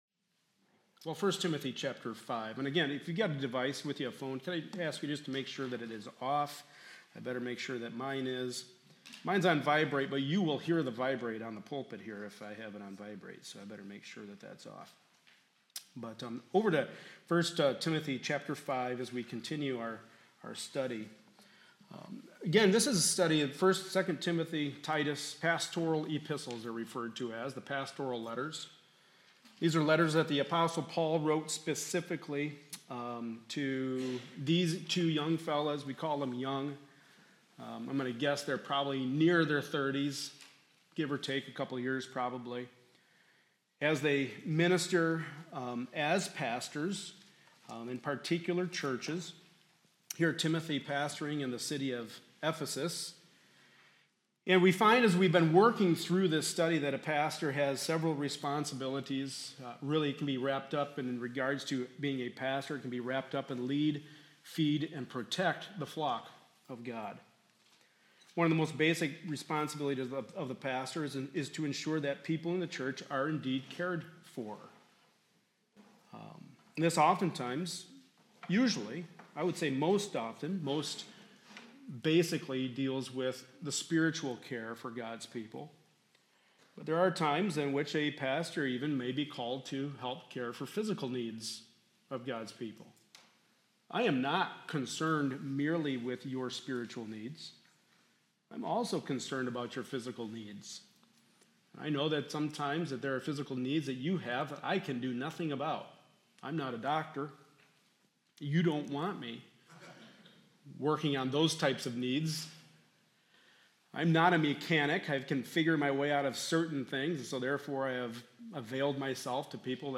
Passage: 1 Timothy 5:3-16 Service Type: Sunday Morning Service